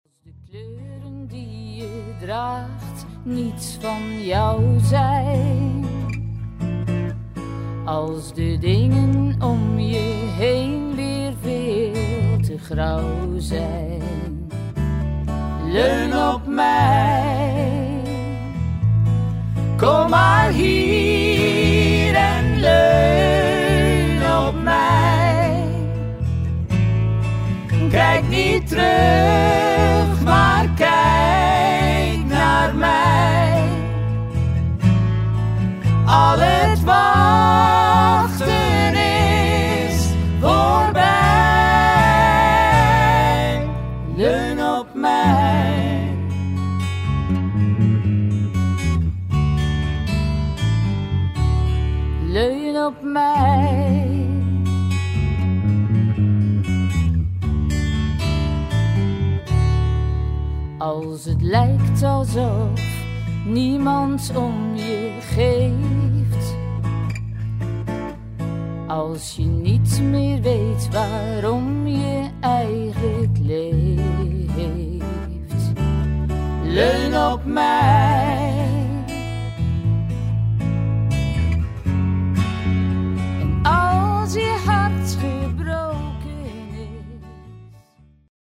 opnames gemaakt in onze vaste oefen-ruimte.